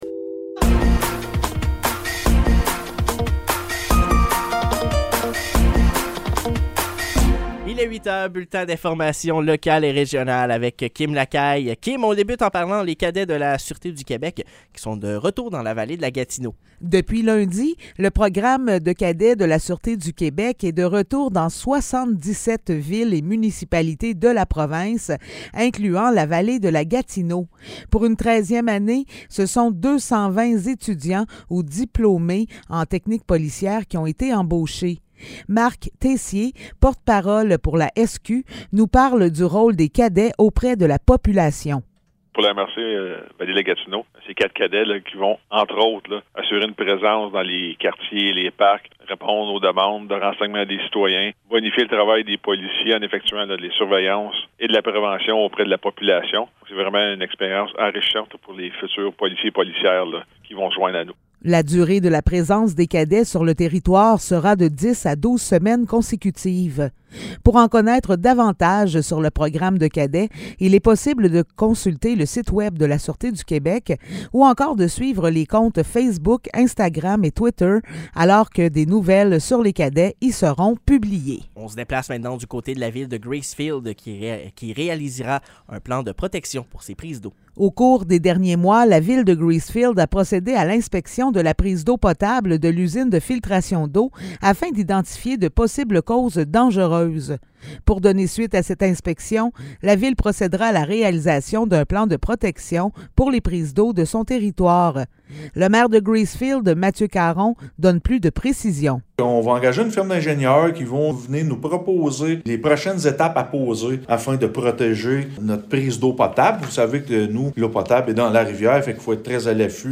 Nouvelles locales - 30 mai 2023 - 8 h